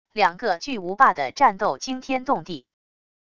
两个巨无霸的战斗惊天动地wav音频